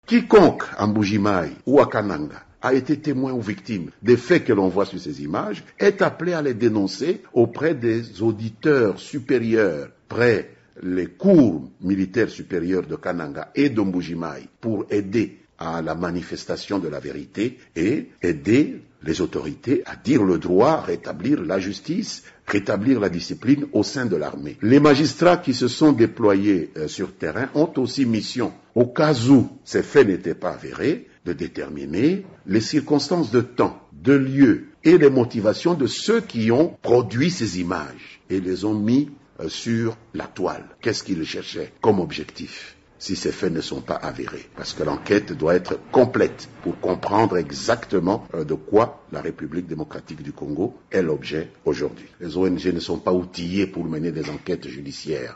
Lambert Mende, ministre de la Communications et des Médias de la RDC, au micro de Top Congo FM, notre partenaire à Kinshasa